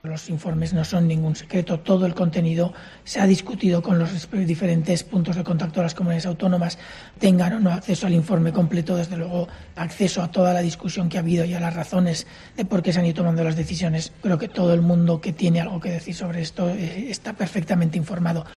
Monólogo de Fernando de Haro
El copresentador de 'La Tarde' de COPE ha valorado la última encuesta de Gad 3 y el pase del País Vasco a la fase 1 en el Plan de Desescalada propuesto por el Gobierno